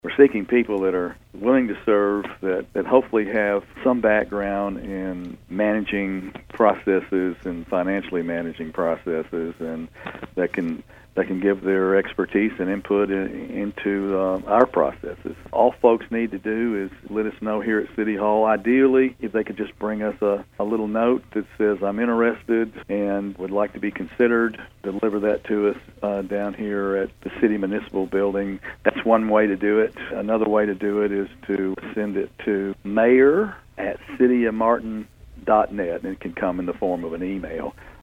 Interim Mayor David Belote tells Thunderbolt News more….